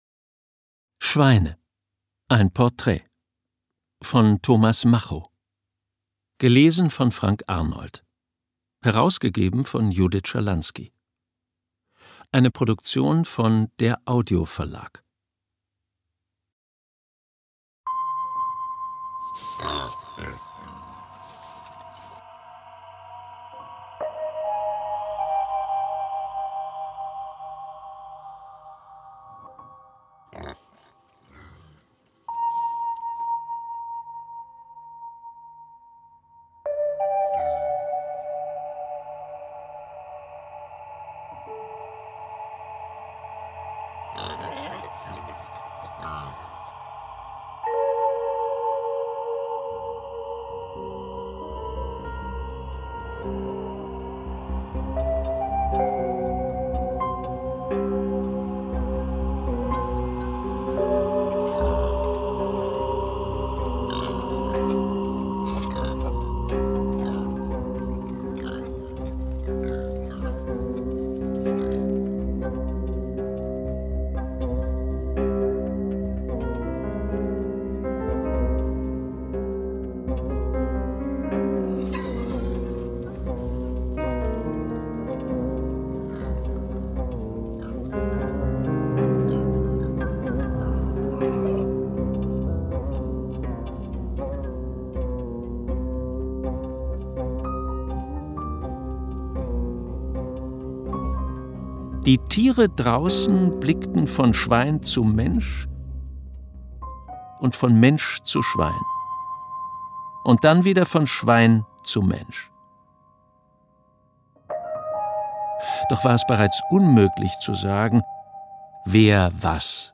Hörbuch: Schweine.
Schweine. Ein Portrait Ungekürzte Lesung